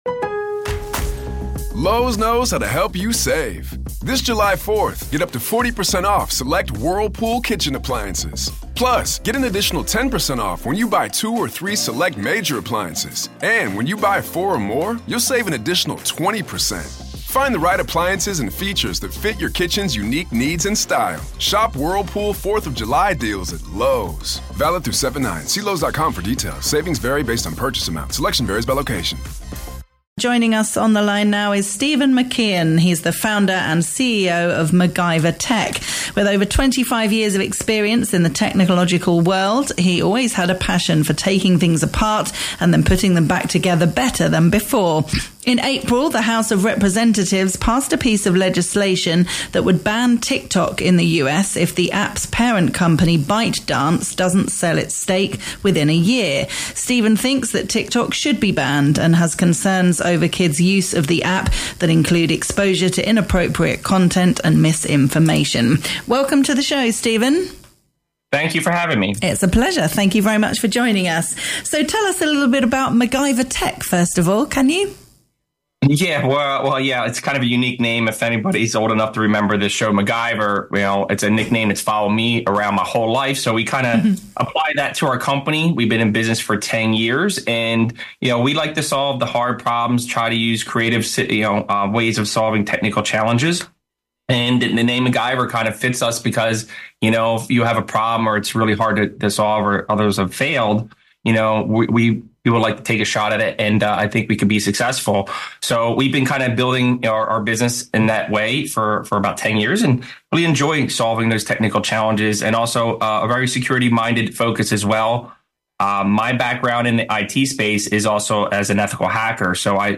Ethical Hacker talking about the huge risks behind apps like TikTok